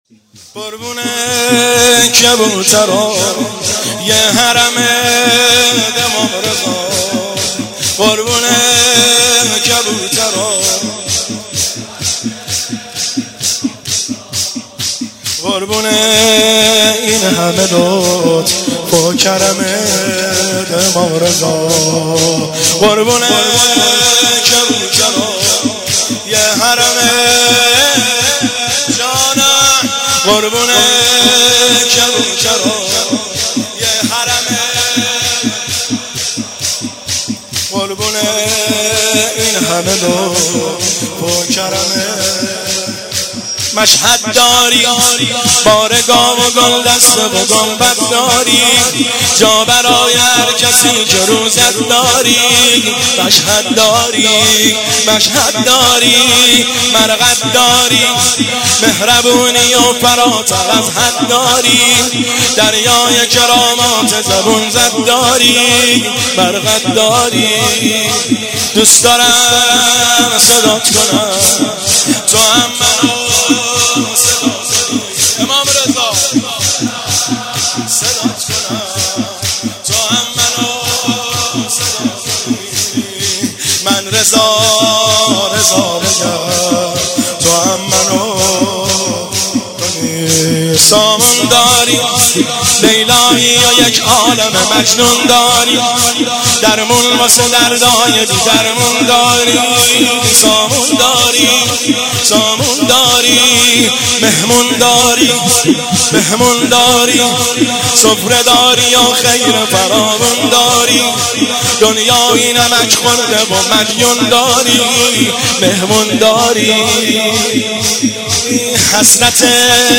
شور- قربون کبوترای حرمت امام رضا